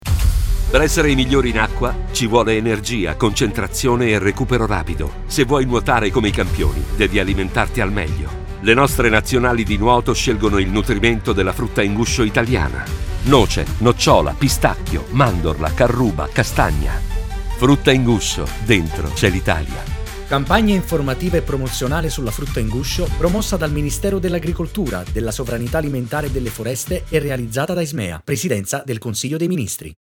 Lo spot radio
Testimonial della campagna due ex atleti olimpionici: la regina dei tuffi Tania Cagnotto e il nuotatore Massimiliano Rosolino.
spot_radio-frutta-in-guscio.mp3